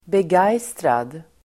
Ladda ner uttalet
Uttal: [beg'aj:strad]